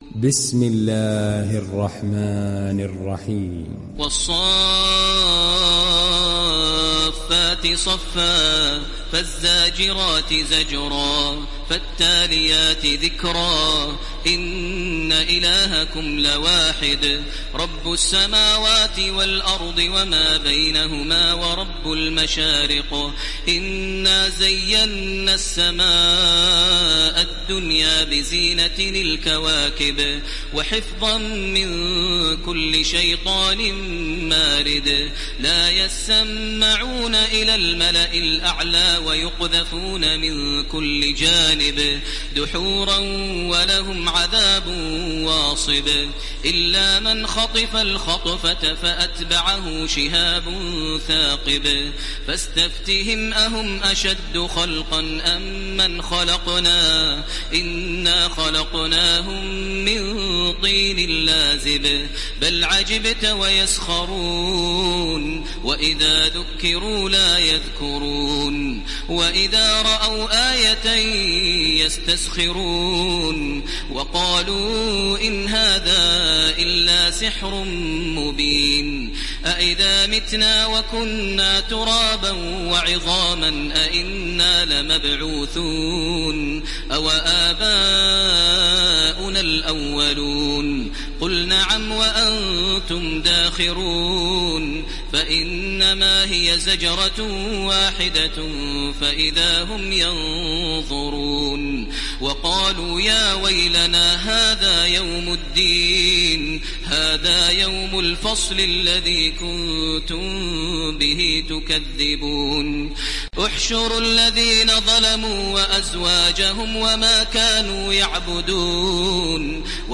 ডাউনলোড সূরা আস-সাফ্‌ফাত Taraweeh Makkah 1430